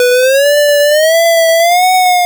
retro_synth_wobble_06.wav